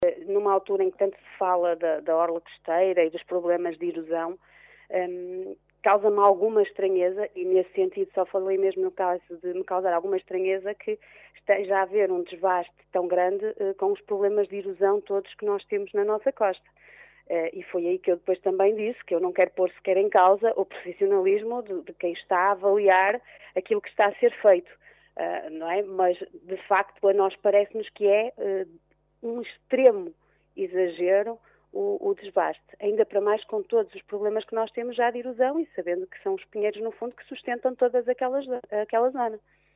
Os vereadores do PSD manifestaram ontem na Reunião de Câmara o seu total desagrado pelo desbaste de inúmeras árvores, nomeadamente “pinheiros e eucaliptos centenários”, que dizem estar a ser feito na Mata Nacional do Camarido, pelo Instituto de Conservação das Florestas e Natureza (ICNF).